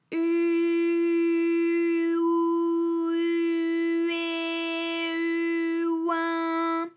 ALYS-DB-001-FRA - First, previously private, UTAU French vocal library of ALYS
u_u_ou_u_eh_u_oin.wav